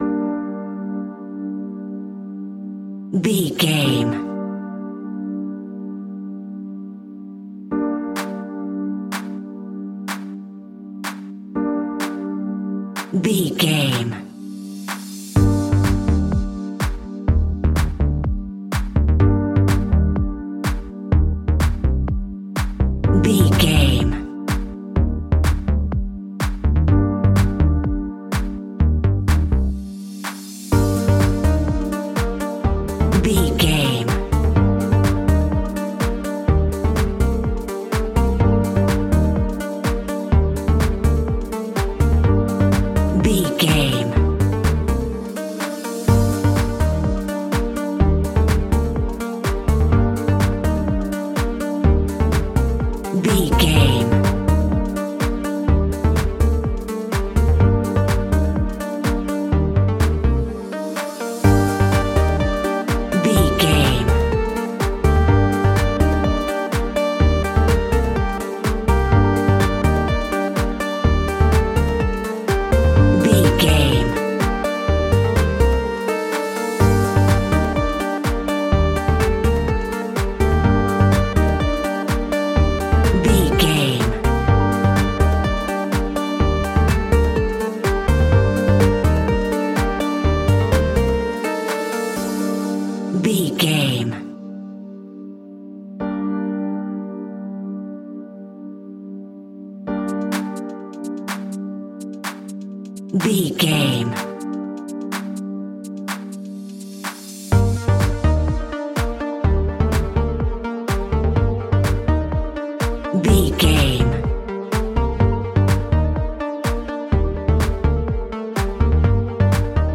Aeolian/Minor
groovy
uplifting
driving
energetic
drum machine
synthesiser
bass guitar
funky house
deep house
nu disco
upbeat
funky guitar
synth bass